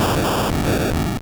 Cri de Voltorbe dans Pokémon Or et Argent.